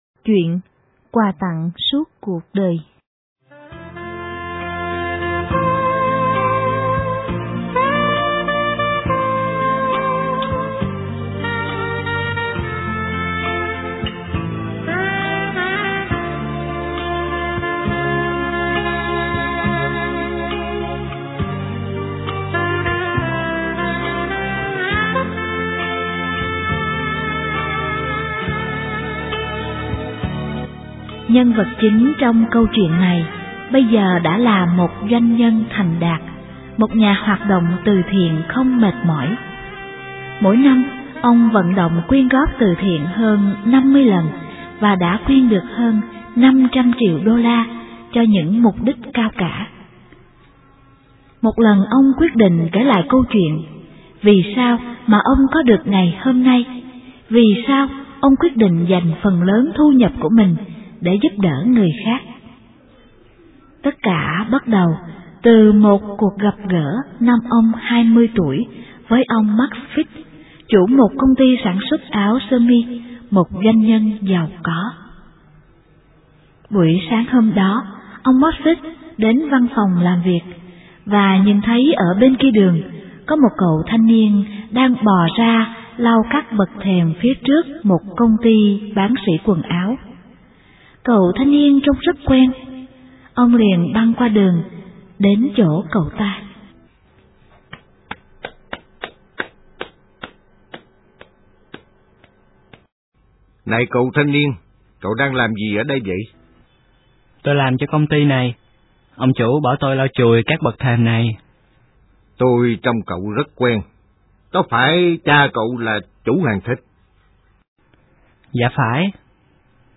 * Thể loại: Sách nói